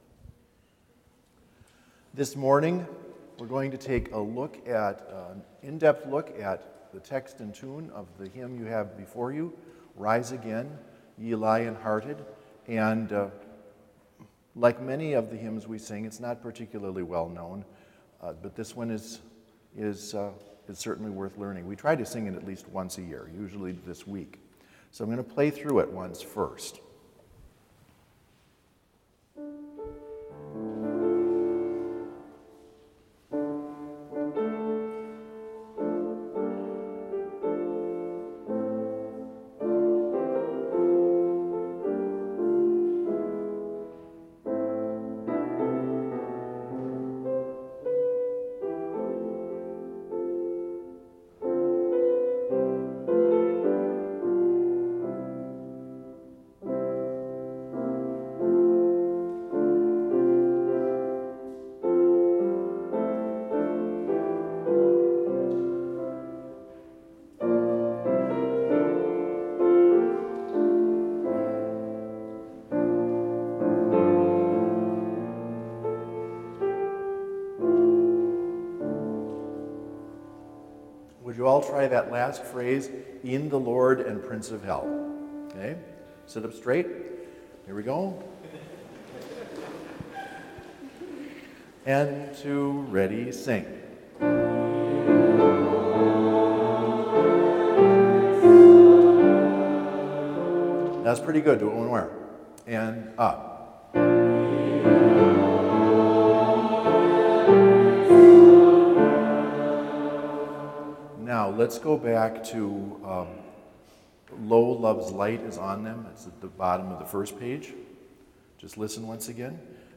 Complete service audio for Chapel - November 5, 2020